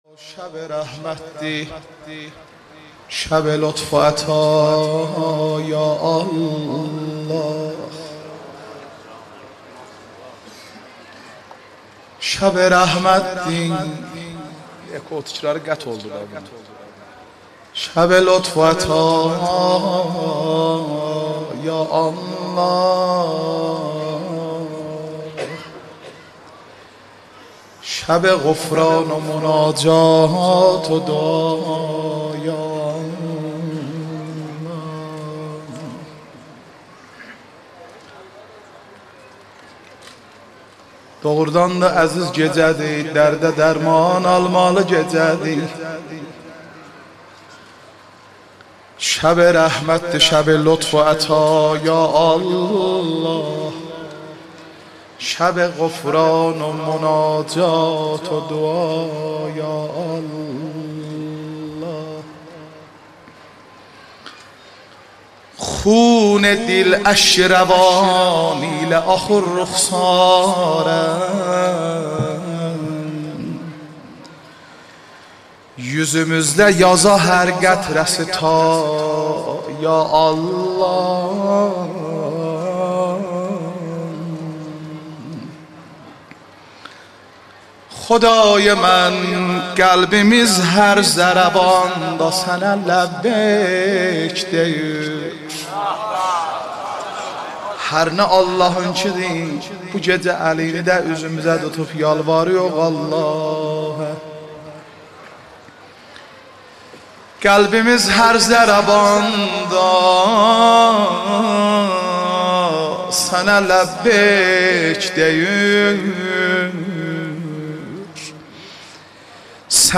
مناجات ماه مبارک رمضان مداحی آذری نوحه ترکی